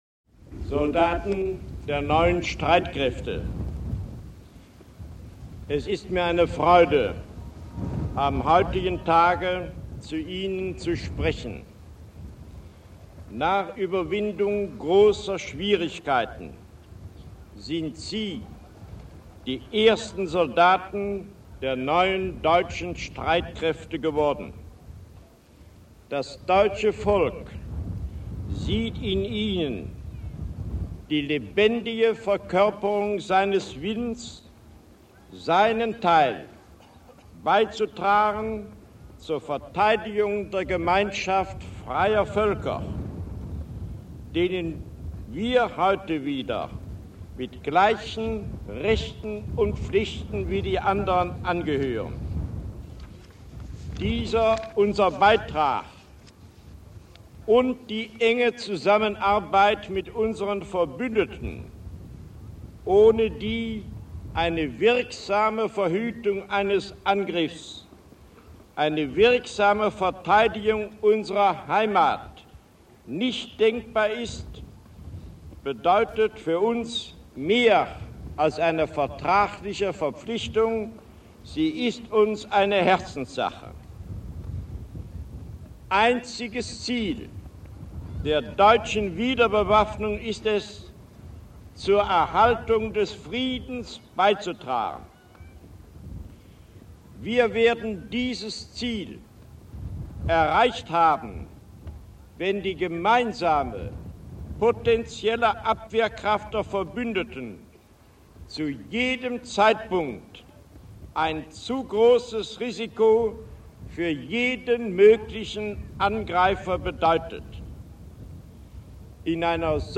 Bundeswehr in Andernach